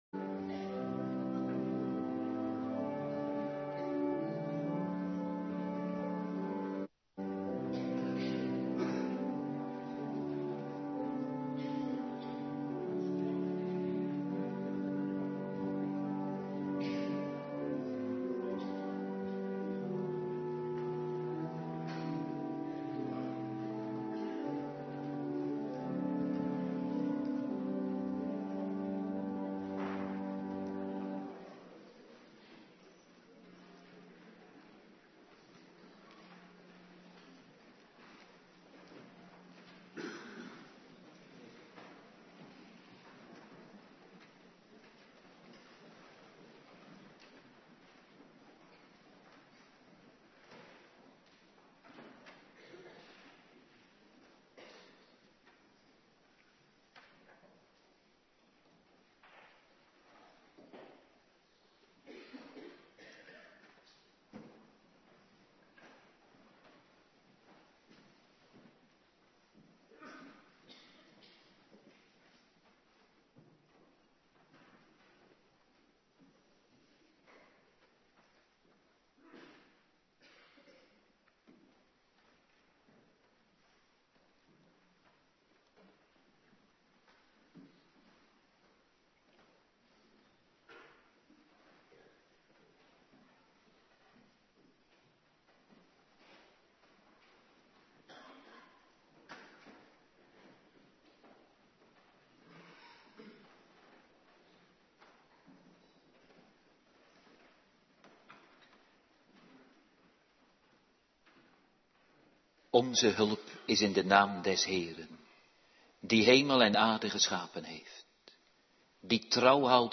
Morgendienst Biddag
09:30 t/m 11:00 Locatie: Hervormde Gemeente Waarder Agenda: Kerkdiensten Terugluisteren Exodus 32:1-14